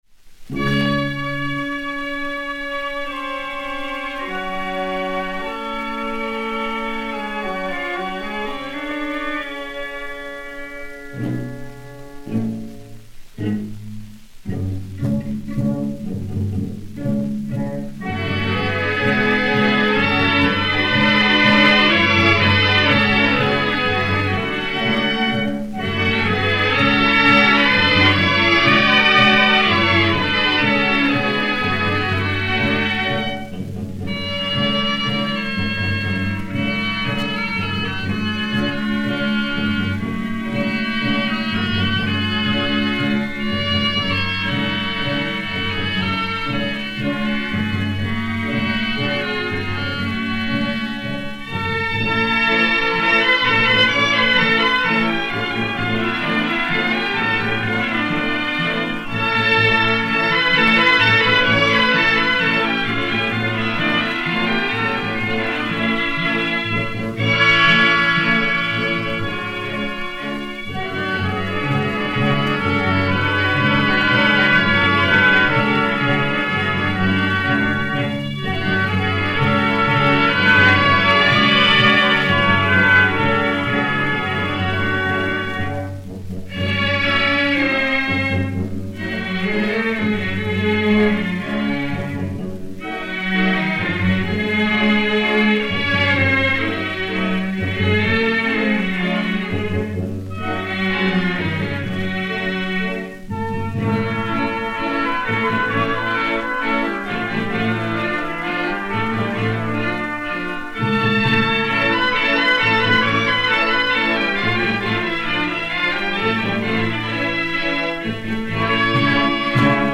Opéra-comique en un acte et en vers, livret de Louis GALLET, musique de Camille SAINT-SAËNS (op. 30).
Orchestre dir.